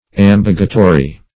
Meaning of ambagitory. ambagitory synonyms, pronunciation, spelling and more from Free Dictionary.
Ambagitory \Am*bag"i*to*ry\